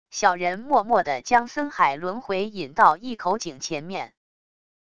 小人默默地将森海轮回引到一口井前面wav音频生成系统WAV Audio Player